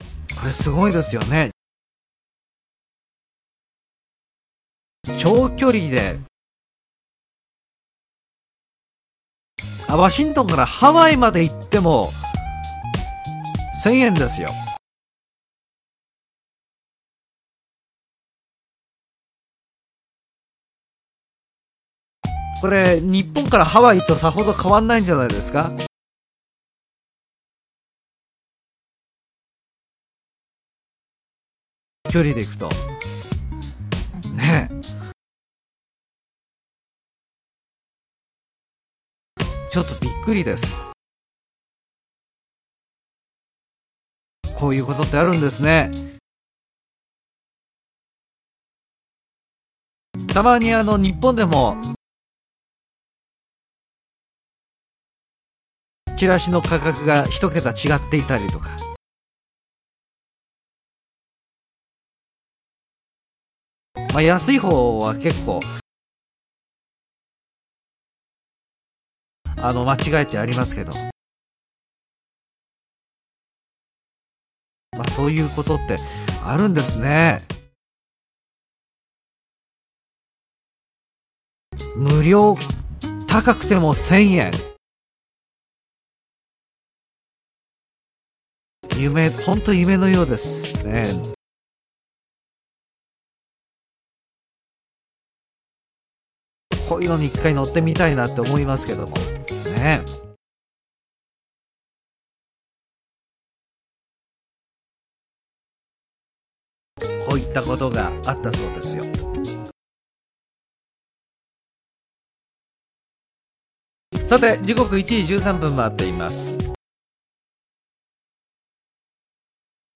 Slow Speed with Pauses